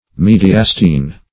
Mediastine \Me`di*as"tine\, Mediastinum \Me`di*as*ti"num\, n.